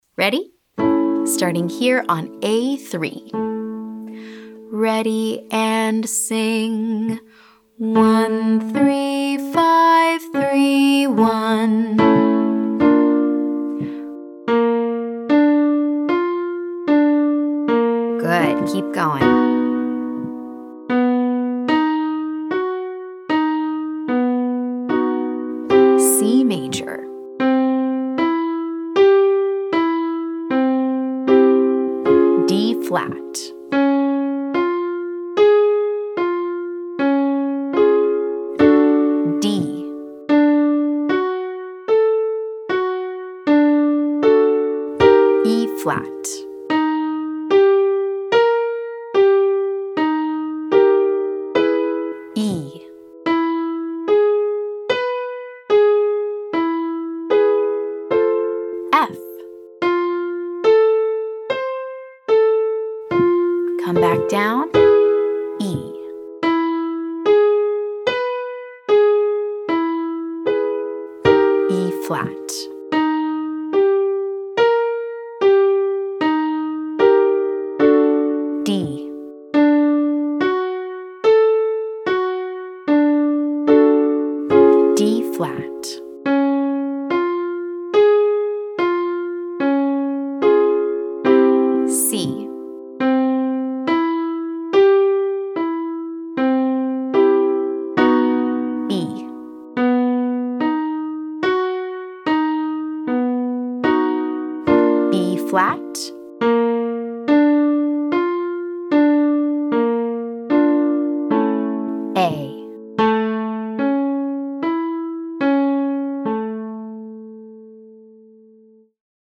Try it without the repeat this time!